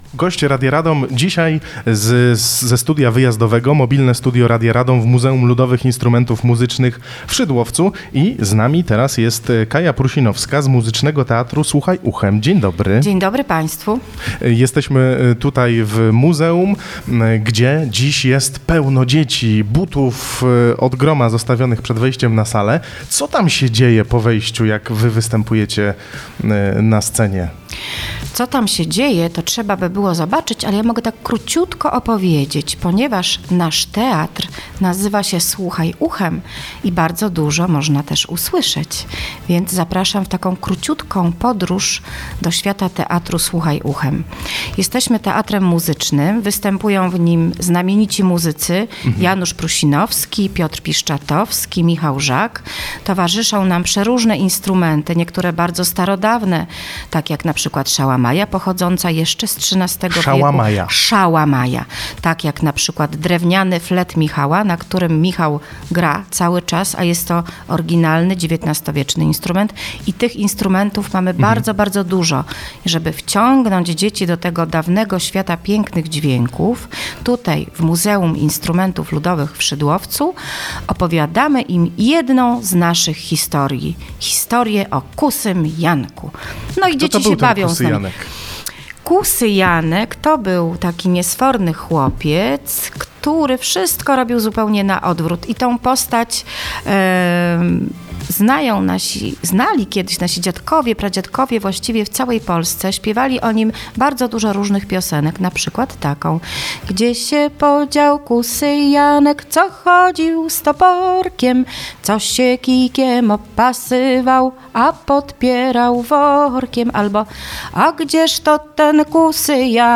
Mobilne Studio Radia Radom dzisiaj w Muzeum Ludowych Instrumentów Muzycznych w Szydłowcu